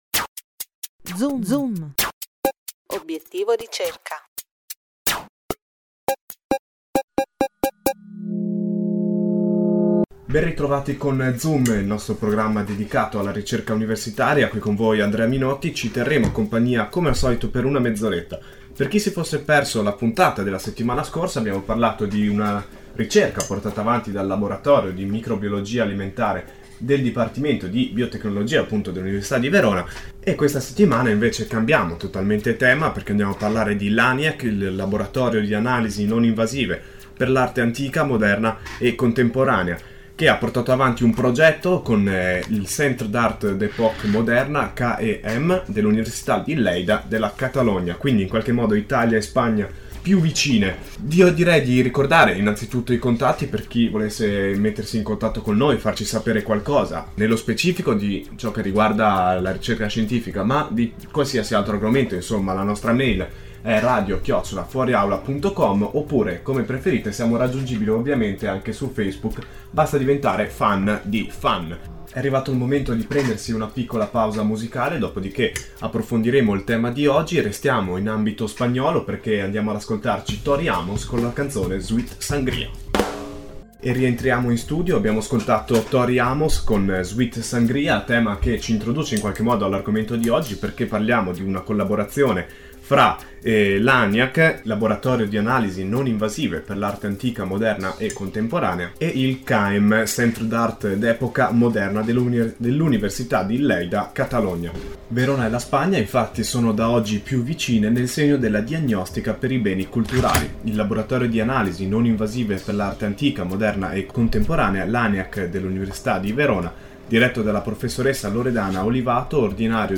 Intervista a Fuori Aula: LANIAC e CAEM sono gemelli Intervista alla web radio di ateneo sullo scambio tra il LANIAC e il CAEM (Centre d'Art d'Epoca Moderna) dell'Università di Lleida (Catalunya) mpeg (it, 16609 KB, 20/04/11) pdf (it, 85 KB, 20/04/11)